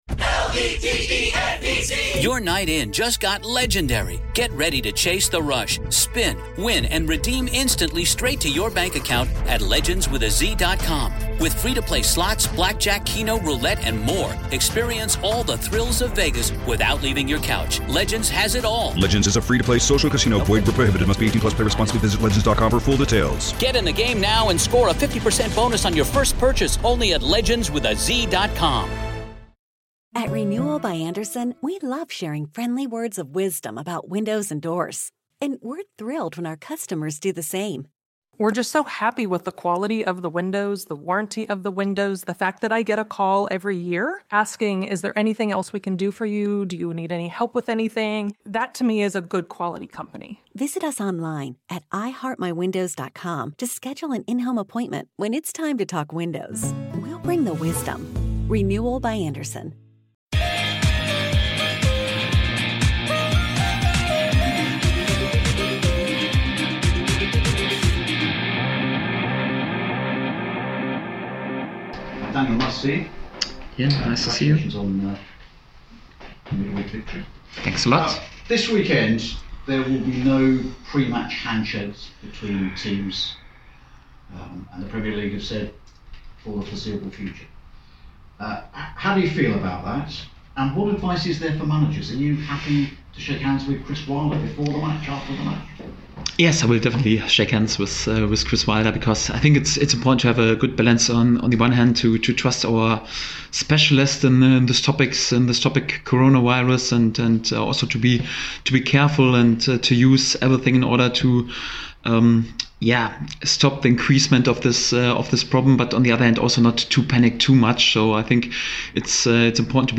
Daniel Farke Press Conference: Sheffield United vs Norwich City
Norwich City head coach Daniel Farke speaks to the press